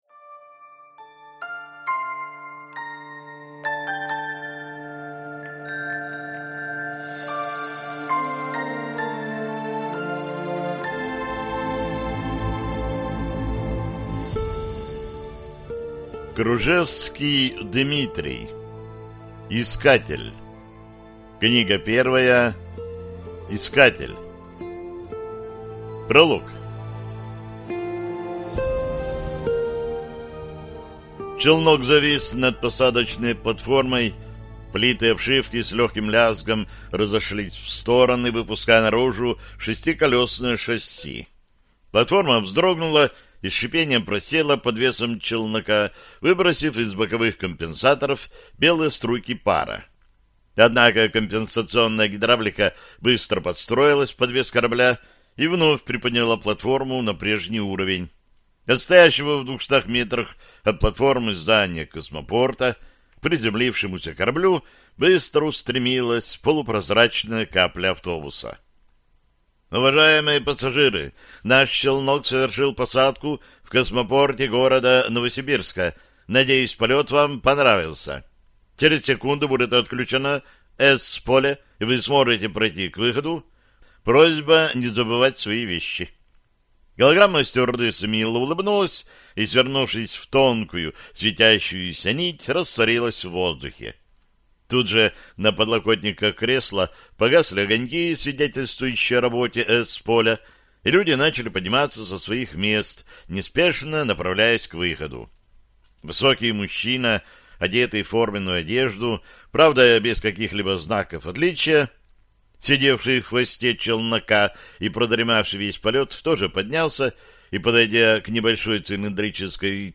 Аудиокнига Искатель | Библиотека аудиокниг